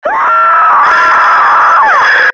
NPC_Creatures_Vocalisations_Robothead [101].wav